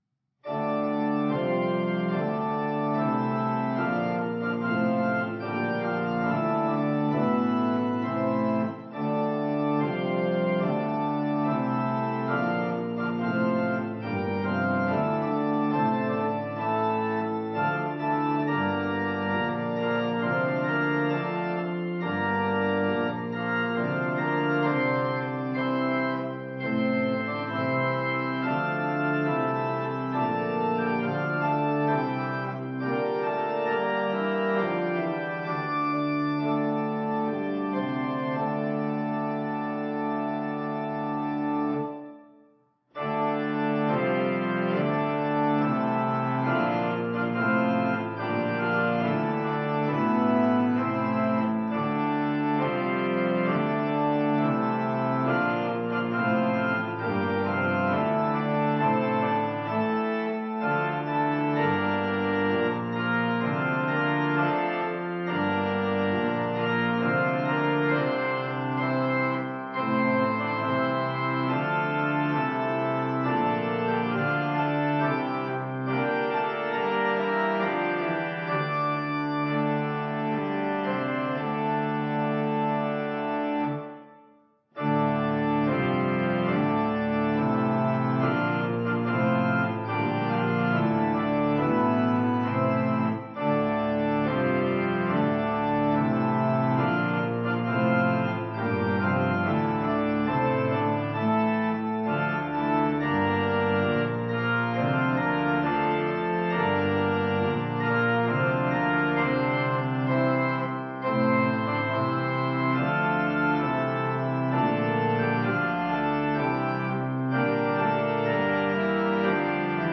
hymn-weary-of-all-trumpeting-tune-by-hugo-distler-organ.mp3